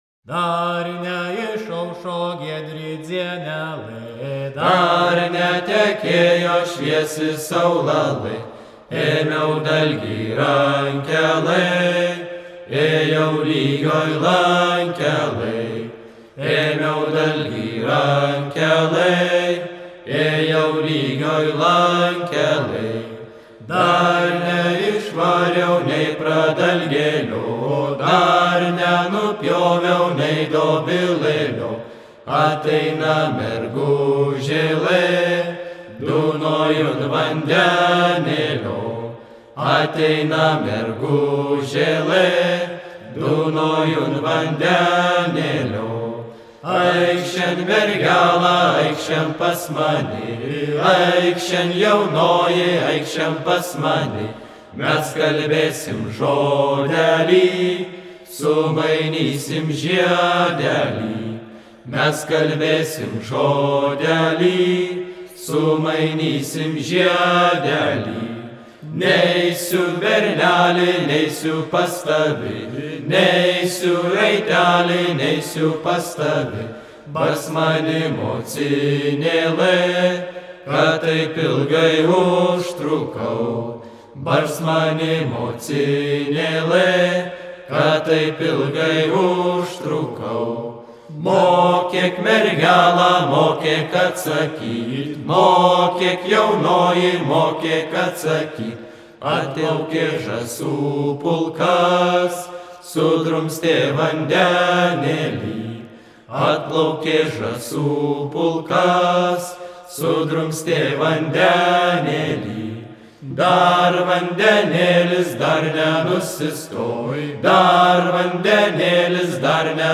vaikinų grupė
Merkinės kultūros namų patalpose, 2011 metais